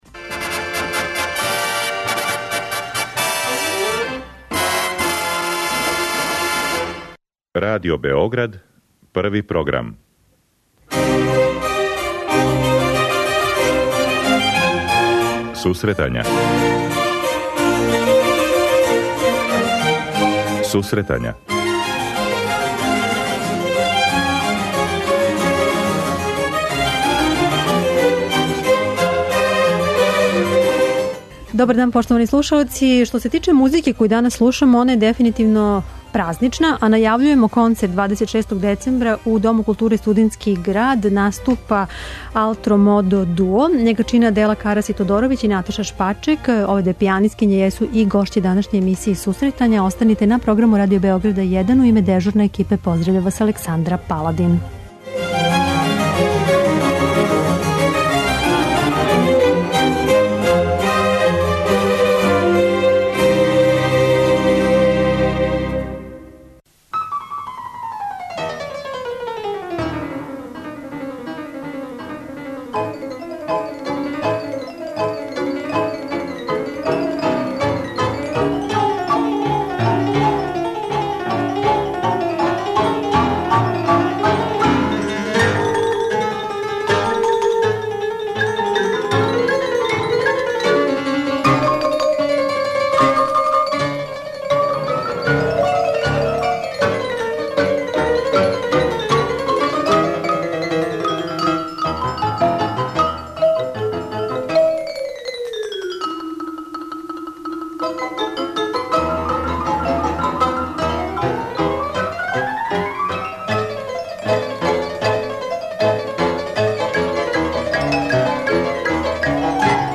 Данас вам представљамо клавирски дуо 'Алтро Модо'.